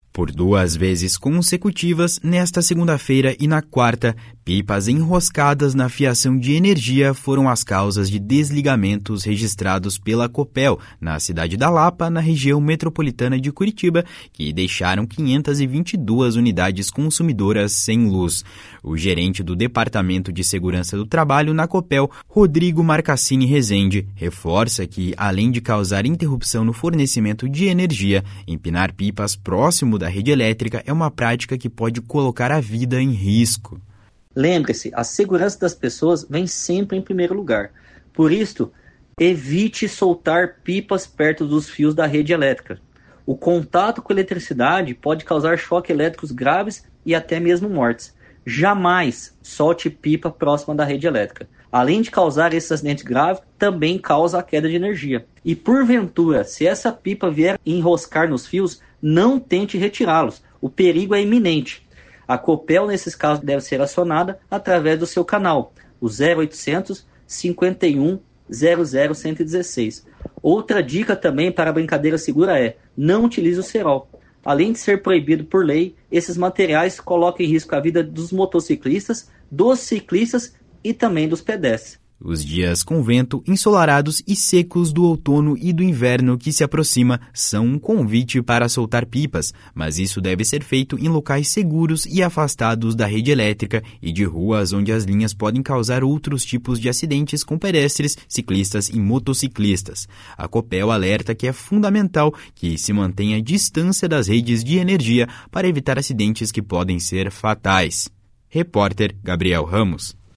A Copel alerta que é fundamental que se mantenha distância das redes de energia para evitar acidentes que podem ser fatais. (Repórter: